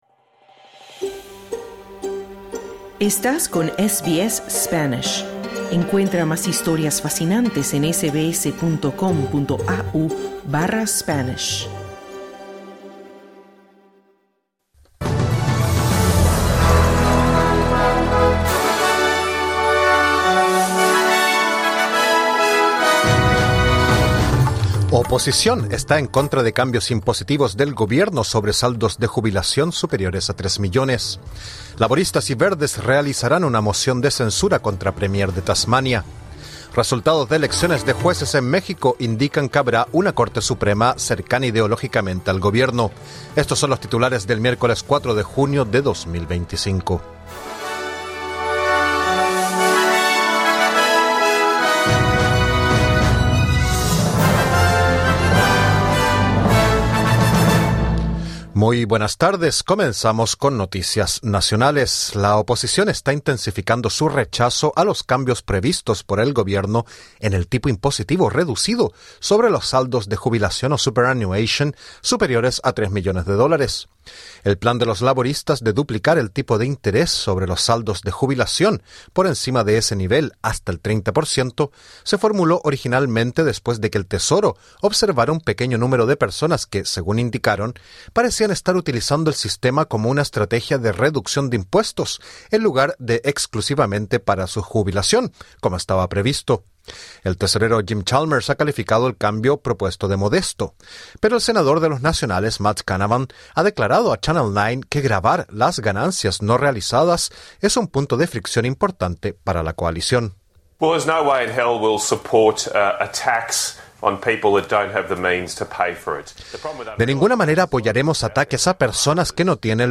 Noticias SBS Spanish | 4 junio 2025